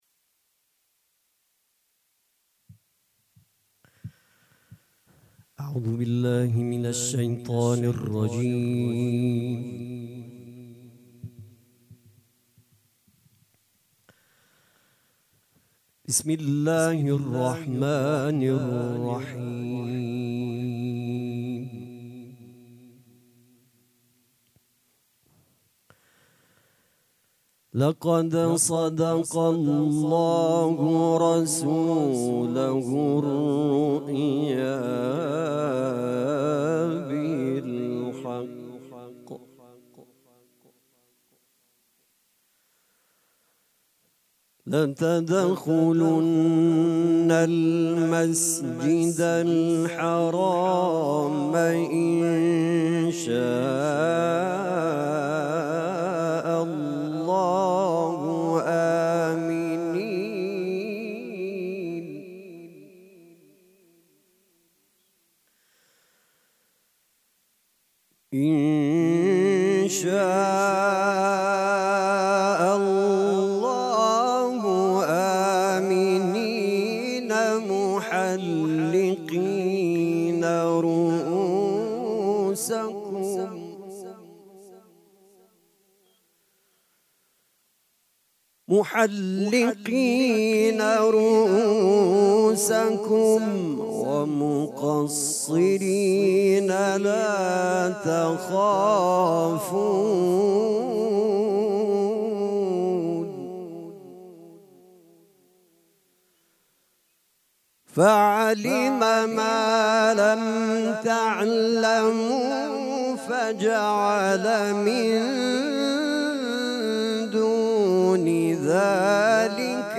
مراسم عزاداری شب اول محرم الحرام ۱۴۴۷
حسینیه ریحانه الحسین سلام الله علیها
قرائت قرآن